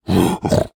minecraft / sounds / mob / piglin / angry1.ogg
angry1.ogg